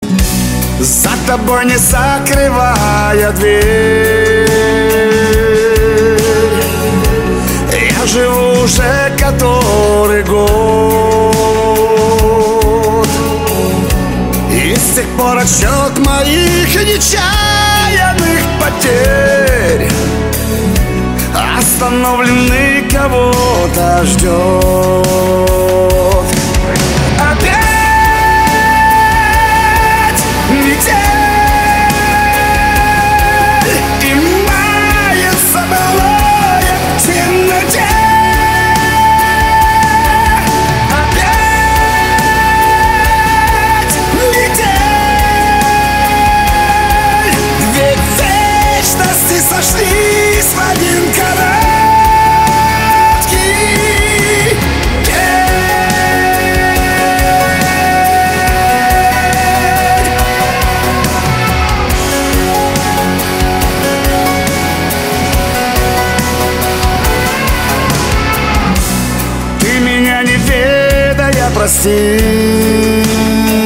мужской вокал
душевные
сильный голос
эстрадные